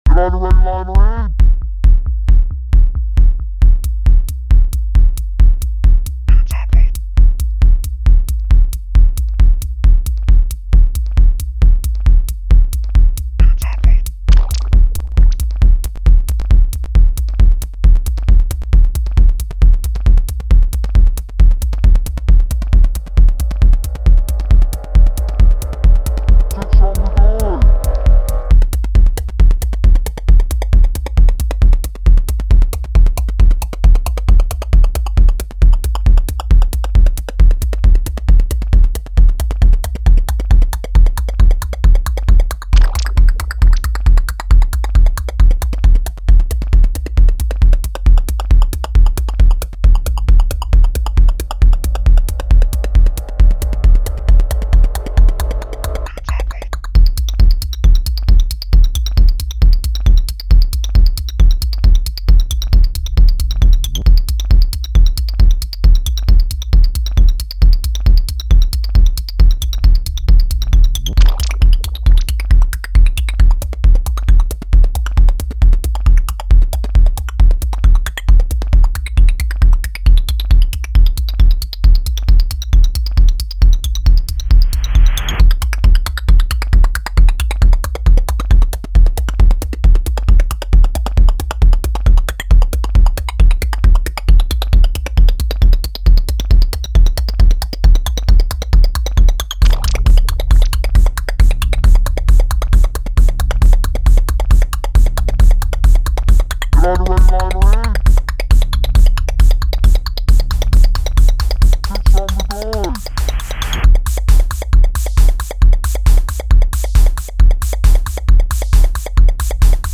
Double time tekno
Kick and mind chatter
Militaristic, regimented, marching
Fascist sci-fi samples to boot